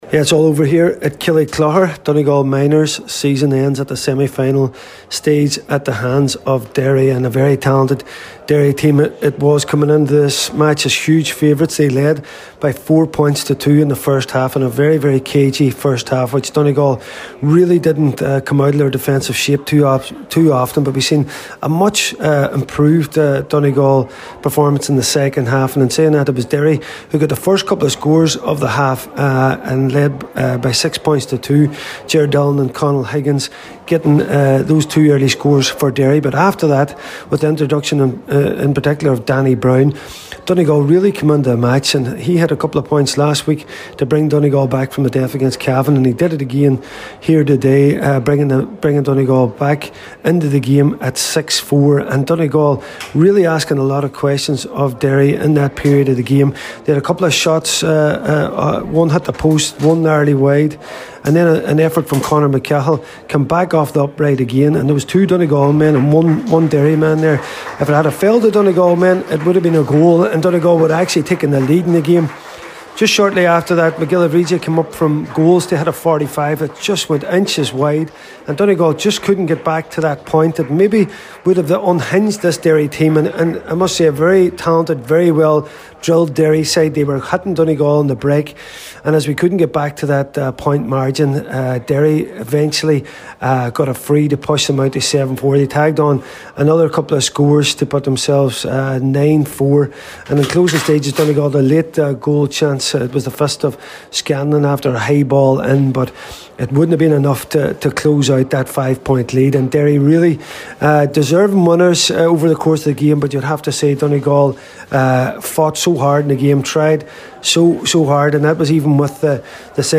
the full time report…